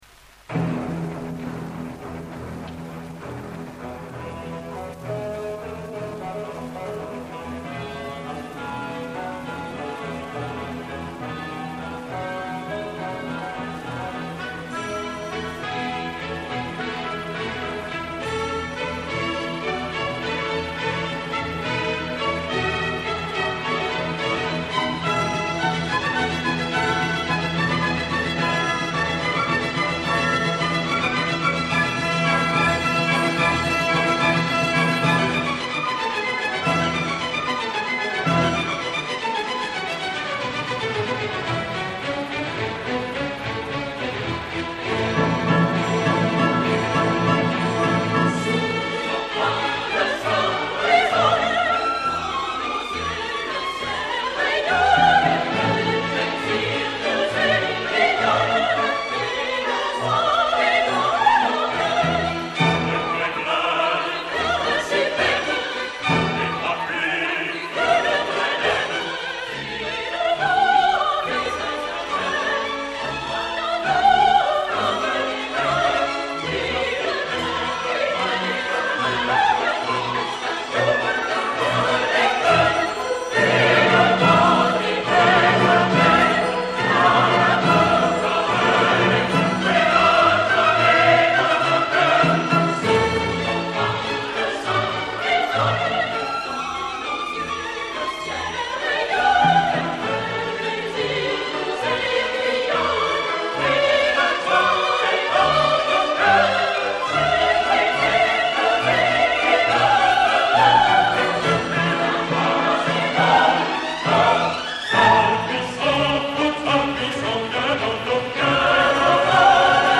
Extraits enregistrés en 1958 (révision musicale d'Henri Büsser) :
Chœurs et Orchestre National de la Radiodiffusion Française dir. Georges Tzipine (chef des chœurs René Alix)
05. Acte II - Chœur dansé